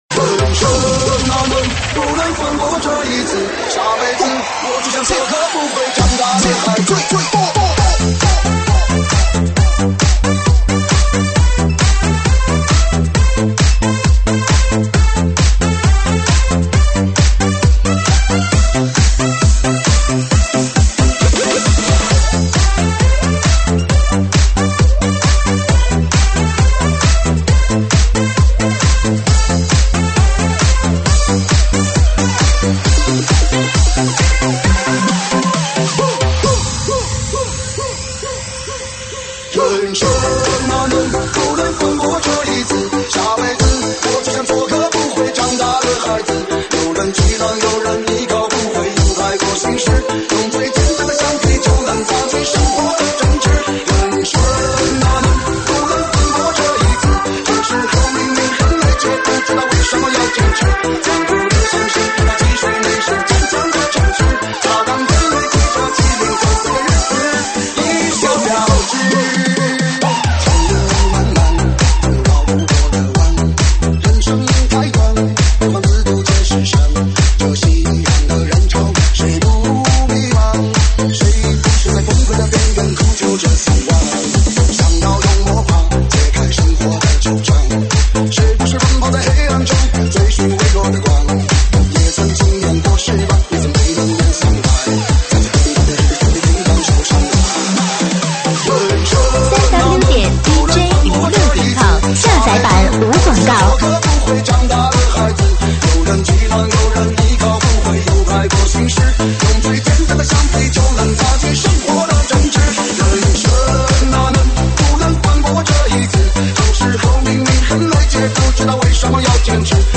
收录于(现场串烧)